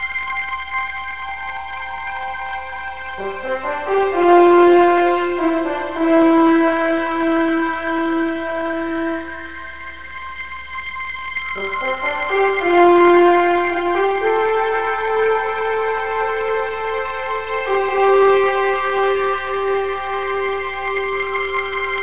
1 channel
00139_Sound_scifi.mp3